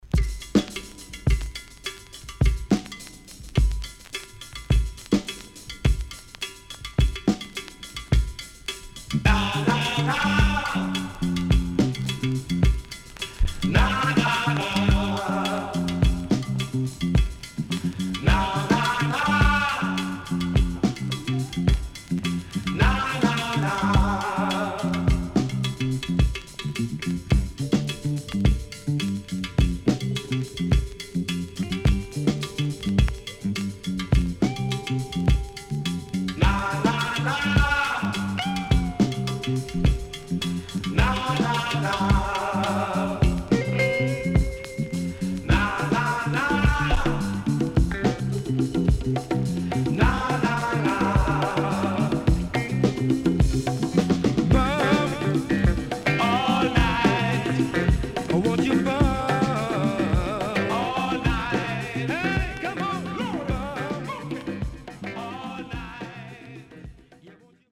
SIDE A:所々ノイズ入ります。